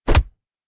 ui_interface_183.wav